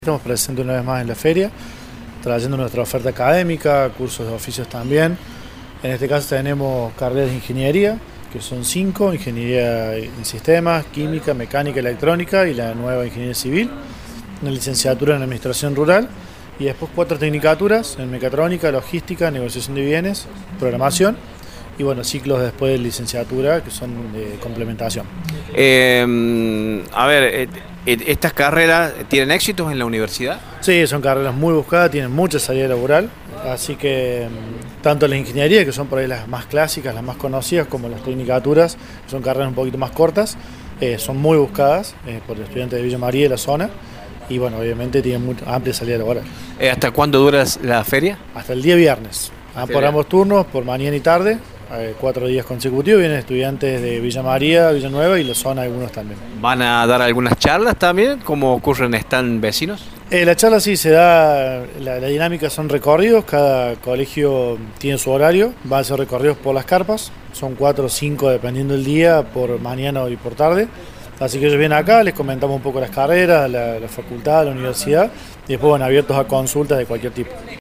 Radio Show dialogó con algunas instituciones que participan del recorrido.